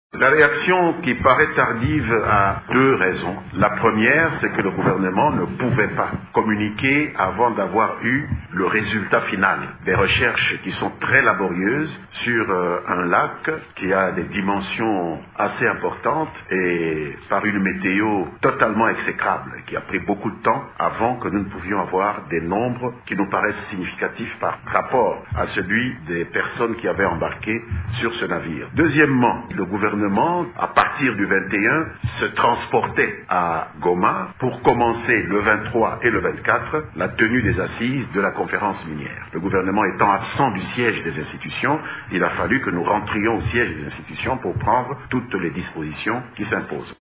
Suivez ici les propos du porte-parole du gouvernement, Lambert Mende Omalanga.
LambertMende-web-bon.mp3